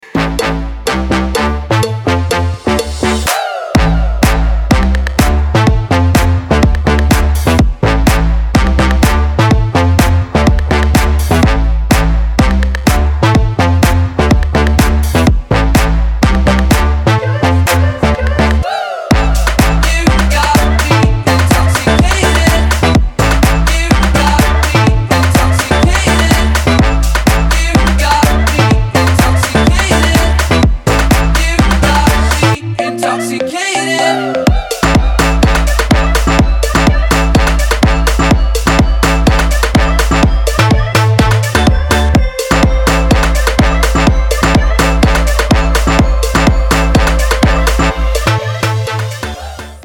• Качество: 256, Stereo
танцевальная музыка